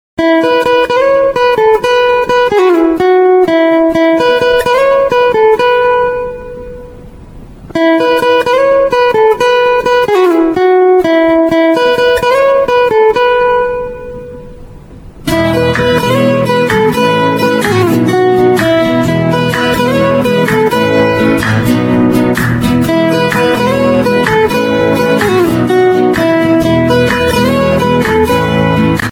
Description: guitar